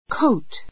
coat 中 A1 kóut コ ウ ト 名詞 ❶ コート , オーバー （overcoat） a fur coat a fur coat 毛皮のコート put on a coat put on a coat コートを着る take off a coat take off a coat コートを脱 ぬ ぐ They don't have coats on.